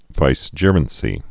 (vīs-jîrən-sē)